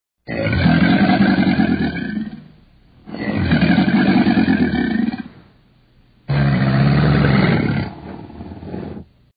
Грозное рычание крокодила